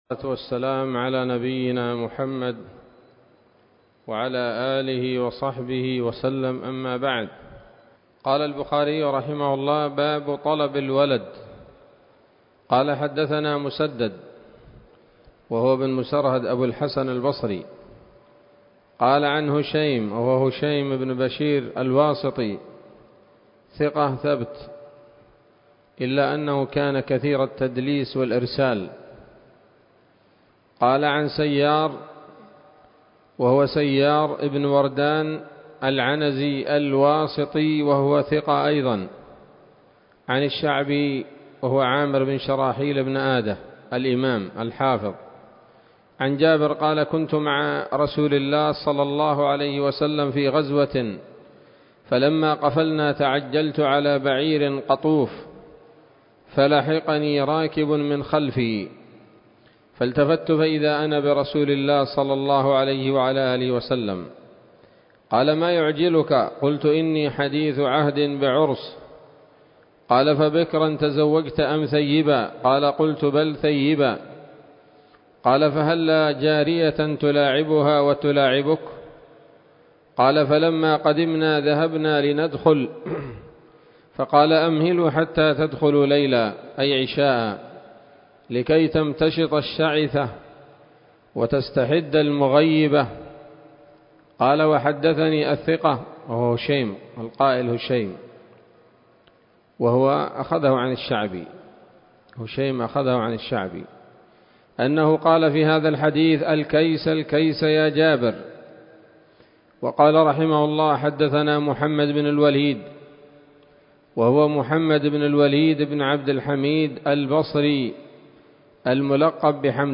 الدرس الرابع والتسعون من كتاب النكاح من صحيح الإمام البخاري